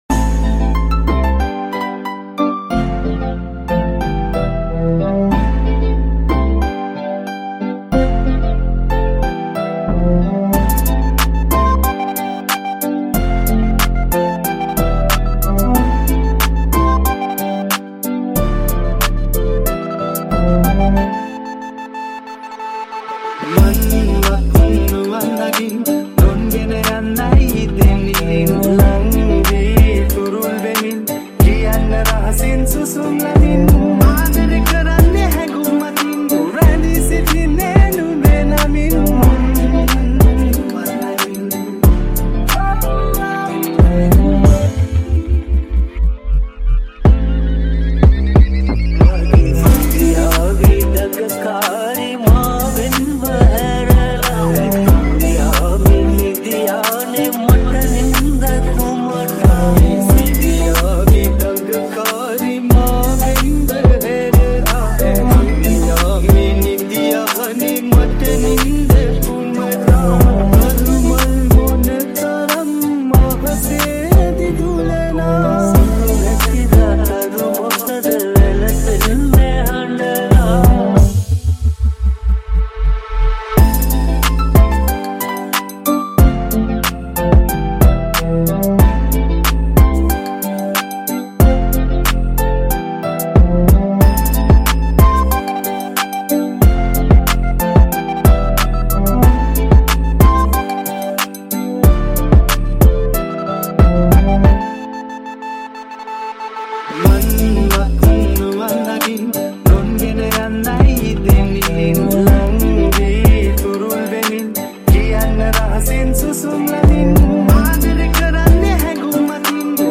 High quality Sri Lankan remix MP3 (3).